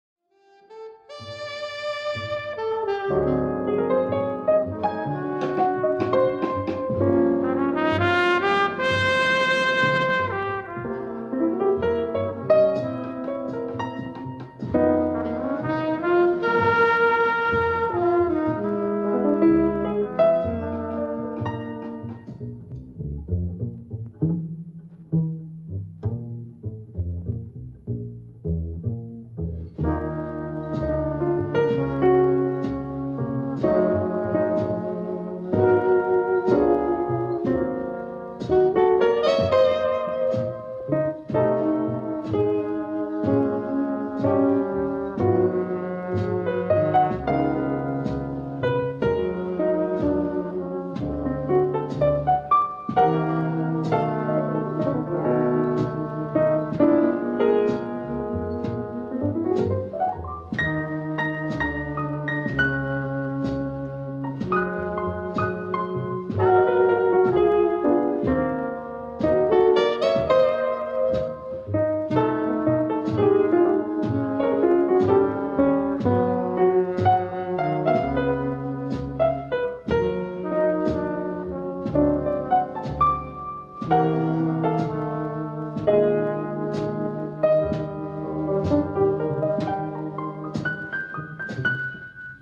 JAZZ (Alrededor de la medianoche)